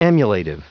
Prononciation du mot emulative en anglais (fichier audio)
Prononciation du mot : emulative